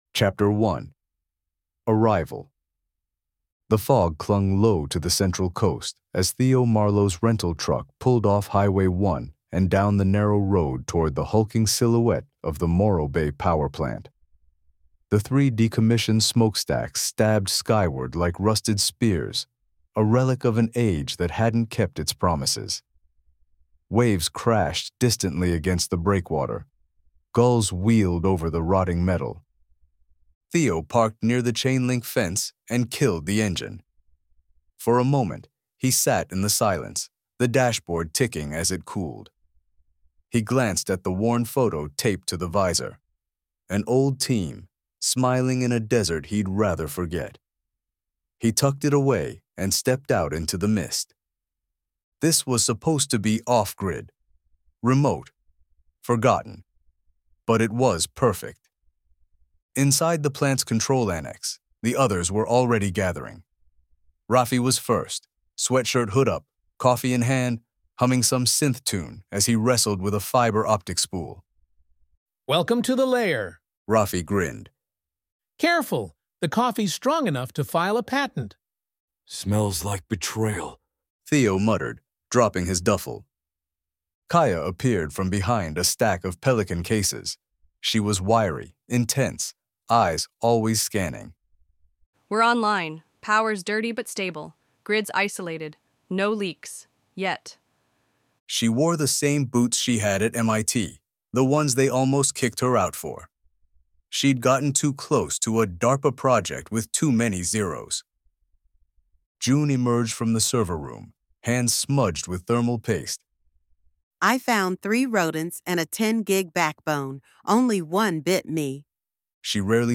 📘 Sample Chapters & Audio Preview
You can read the excerpt or listen to it with full AI narration.